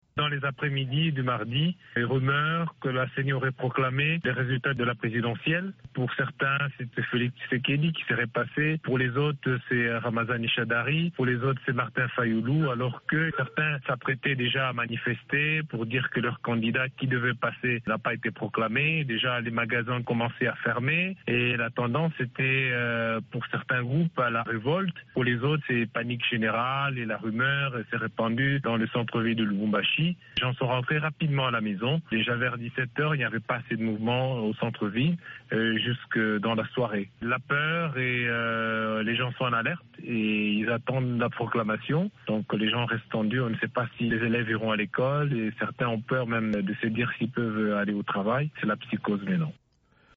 Un vent de panique s’est aussi emparé de Lubumbashi. Le point avec correspondant sur place